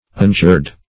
Search Result for " ungird" : The Collaborative International Dictionary of English v.0.48: Ungird \Un*gird"\, v. t. [1st pref. un- + gird.]